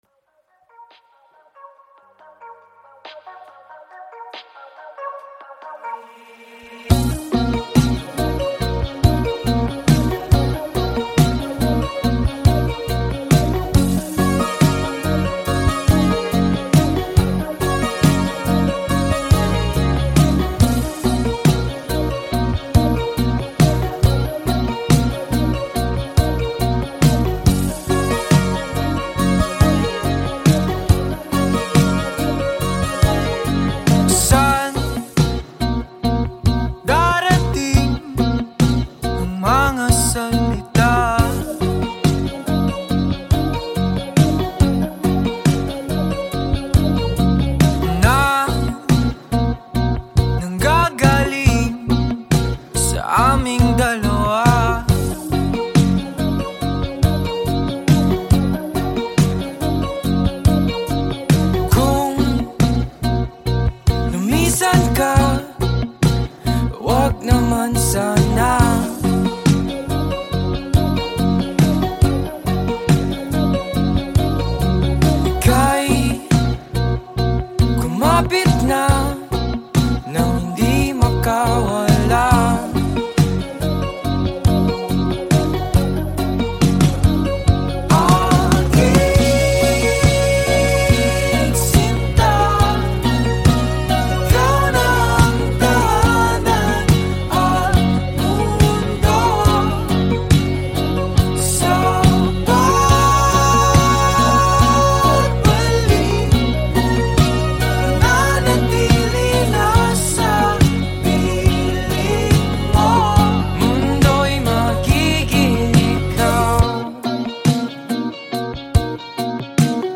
indie band